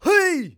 xys长声2.wav 0:00.00 0:00.59 xys长声2.wav WAV · 51 KB · 單聲道 (1ch) 下载文件 本站所有音效均采用 CC0 授权 ，可免费用于商业与个人项目，无需署名。
人声采集素材